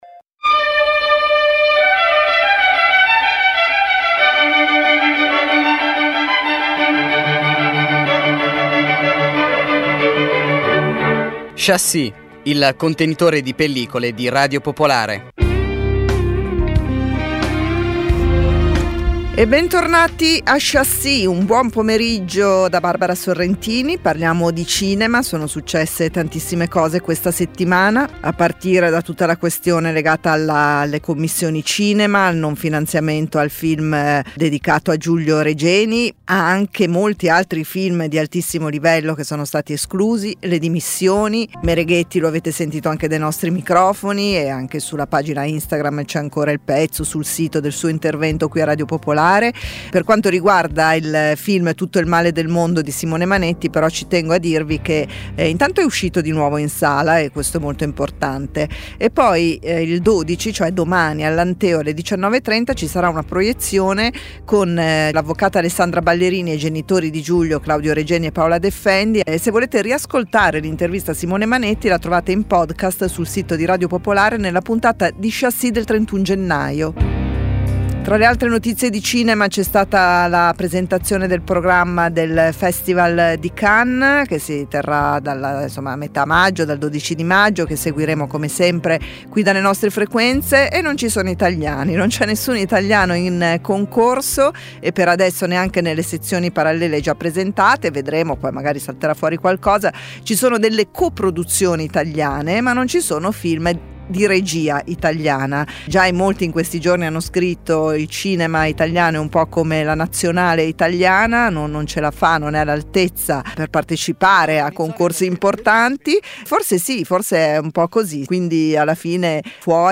Ogni sabato offre un'ora di interviste con registi, attori, autori, e critici, alternando parole e musica per evocare emozioni e riflessioni cinematografiche. Include notizie sulle uscite settimanali, cronache dai festival e novità editoriali. La puntata si conclude con una canzone tratta da colonne sonore.